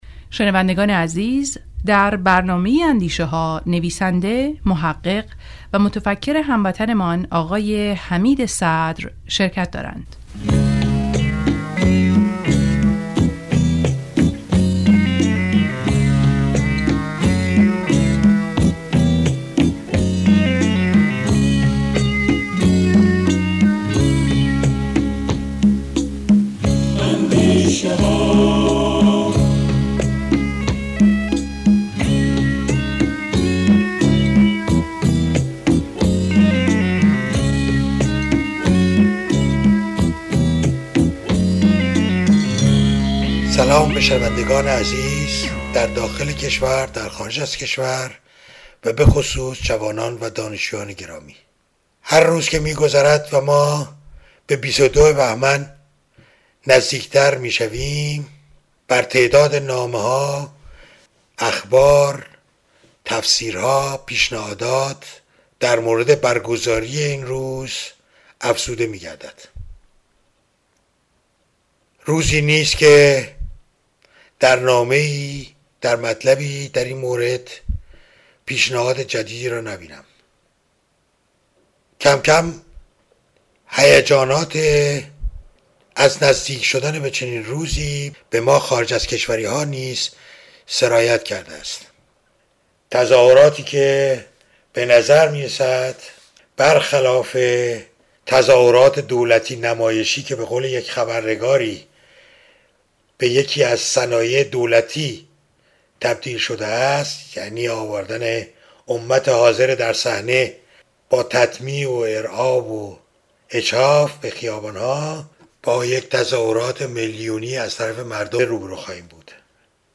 برای شنیدن گفتار رادیویی لطفا اینجا کلیک کنید